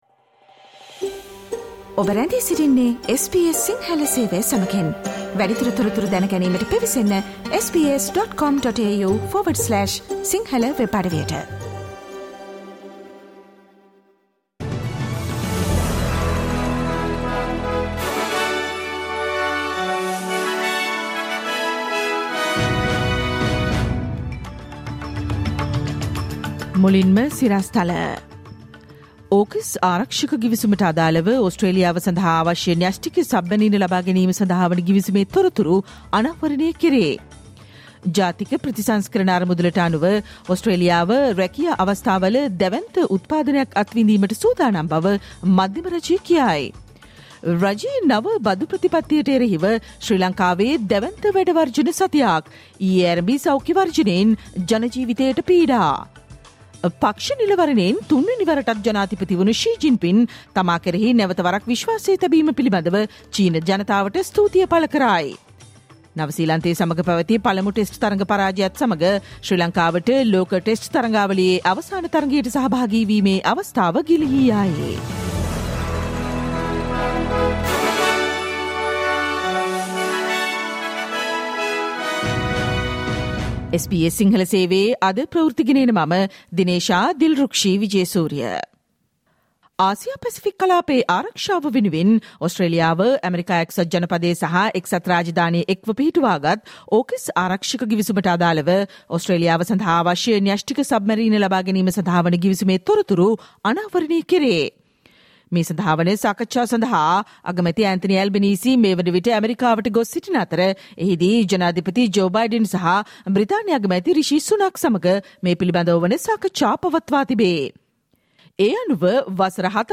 Listen to the SBS Sinhala Radio news bulletin on Tuesday 14 March 2023